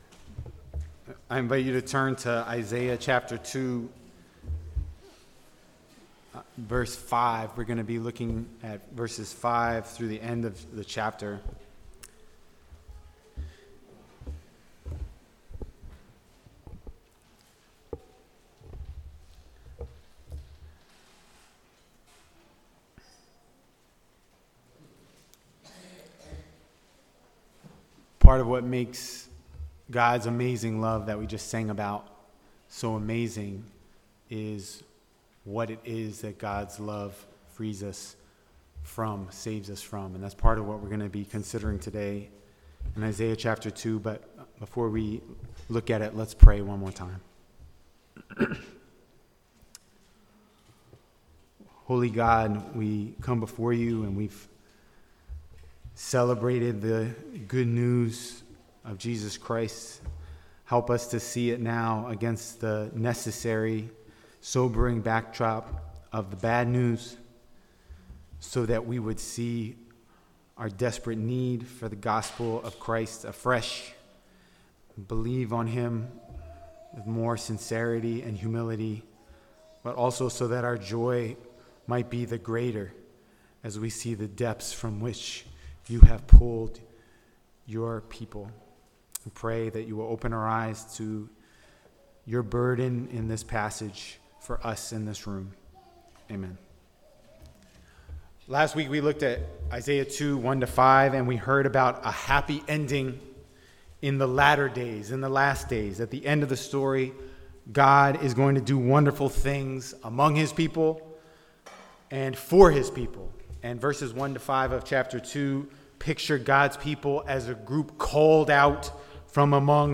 Sermons | The Bronx Household of Faith
Service Type: Sunday Morning